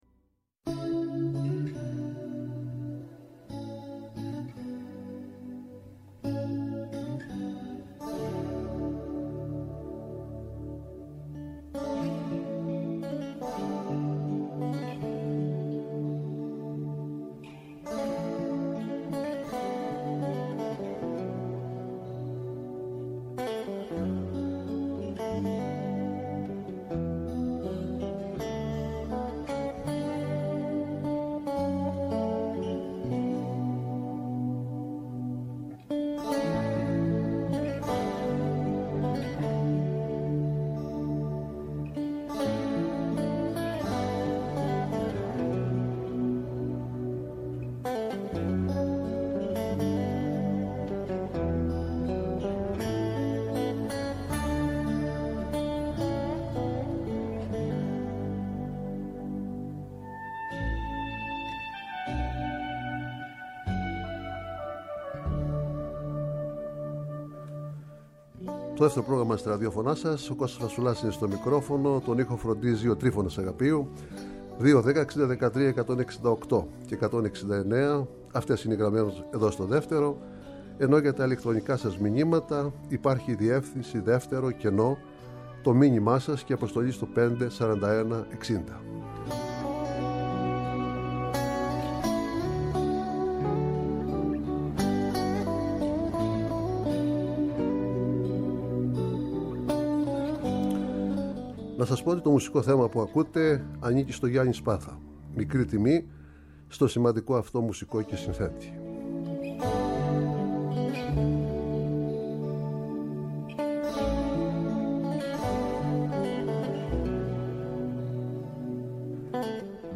Live στο Studio
Συνεντεύξεις